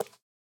sounds / mob / frog / step3.ogg